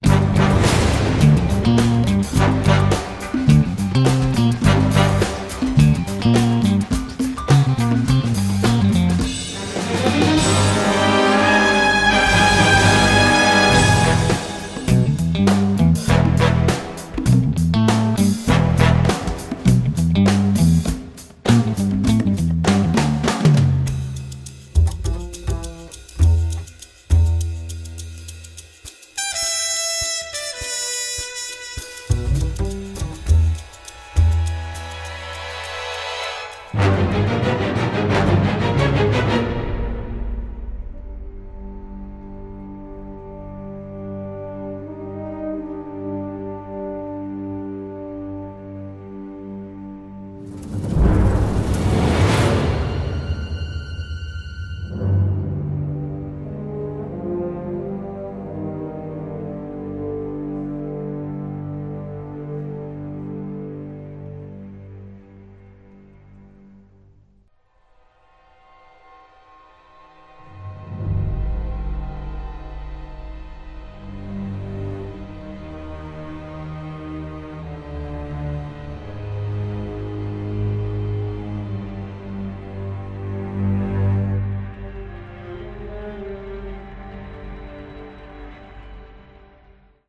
propulsive orchestral score
drenched in delightful old school film-noir vibes